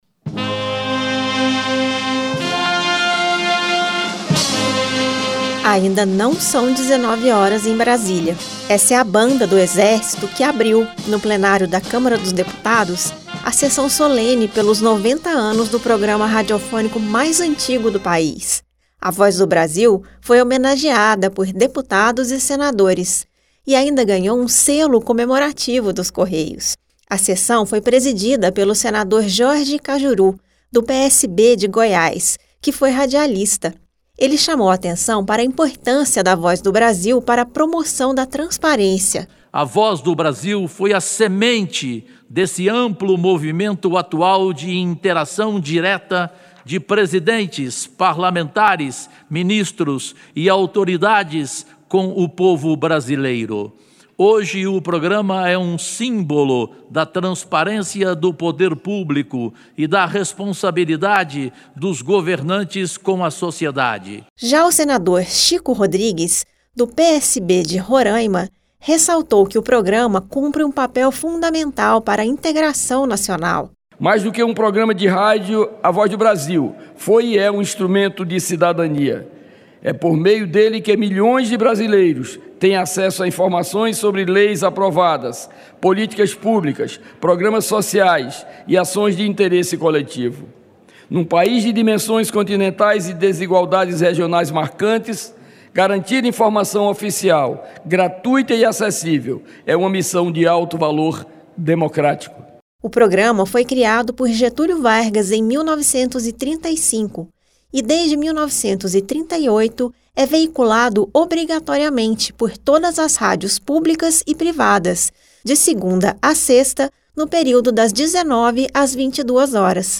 Senadores, deputados, autoridades, jornalistas e servidores públicos do Executivo, Legislativo e Judiciário participaram na manhã de terça-feira (5), no Plenário da Câmara dos Deputados, de uma sessão do Congresso Nacional em comemoração dos 90 anos do programa de rádio A Voz do Brasil. Presidindo a sessão, o senador Jorge Kajuru (PSB-GO) destacou a importância do programa para a promoção da transparência. Na ocasião, foi lançado um selo dos Correios alusivo à comemoração.